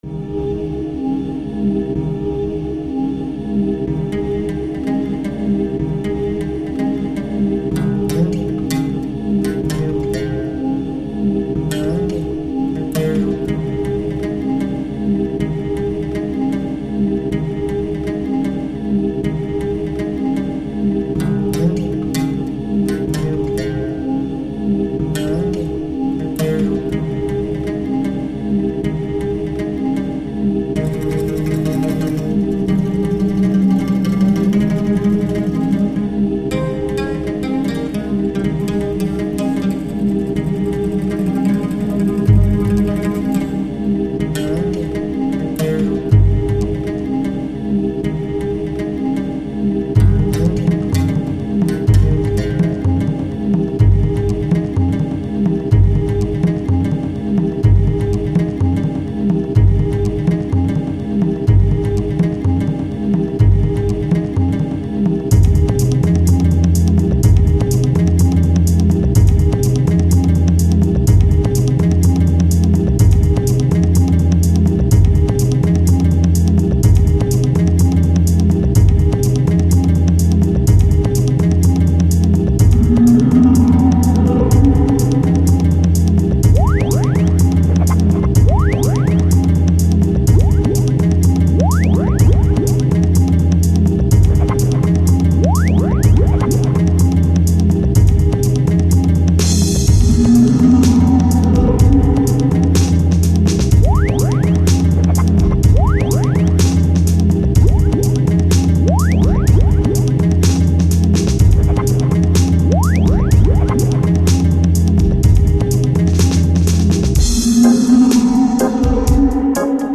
son home studio